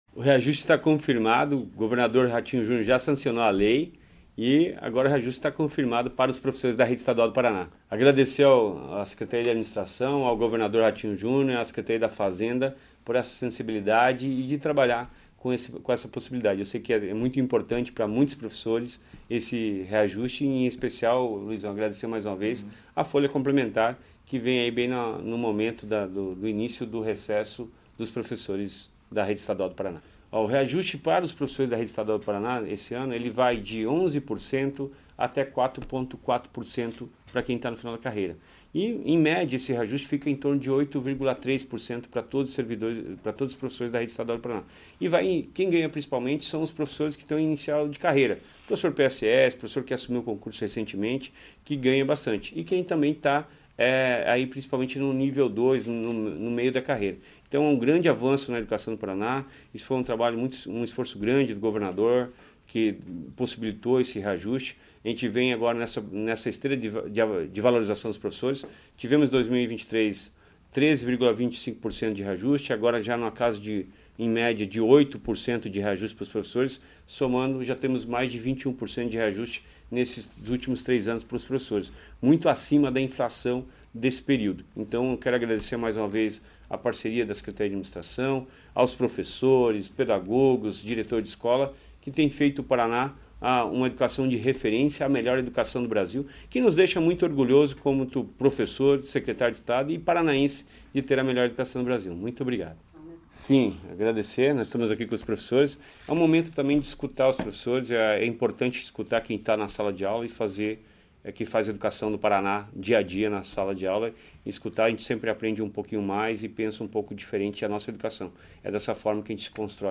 Sonora do secretário da Educação, Roni Miranda, sobre o anúncio da data do pagamento do reajuste salarial dos professores da rede estadual